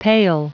Prononciation du mot pale en anglais (fichier audio)